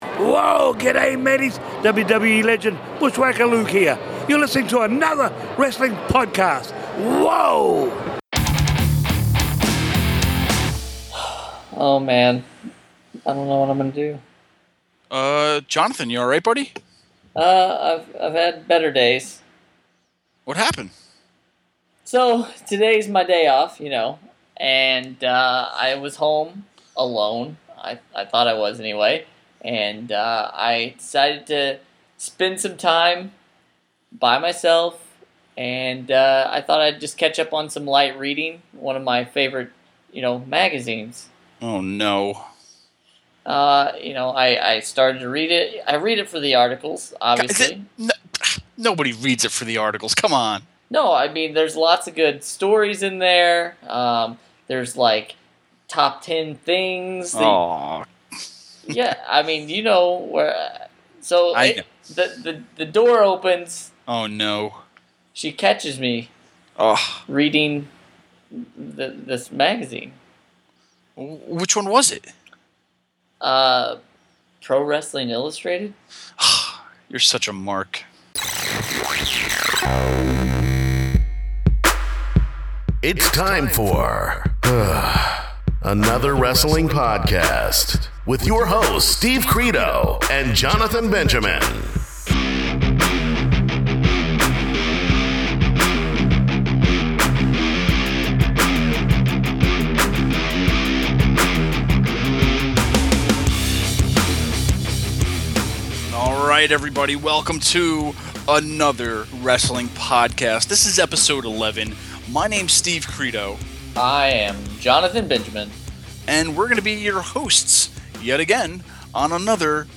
We are also joined by former WWE Women's Champion Debra McMichael Marshall!